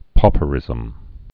(pôpə-rĭzəm)